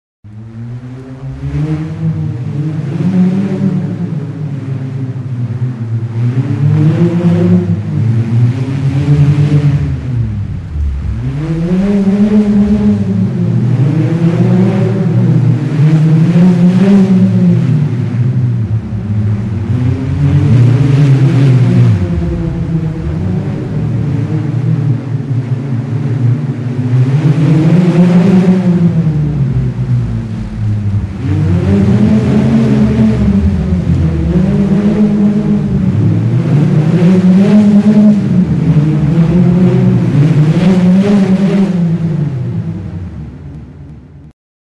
FURRUFARRA, burruna | Soinuenea Herri Musikaren Txokoa
Video Audio Bi burrunekin egindako grabazioa.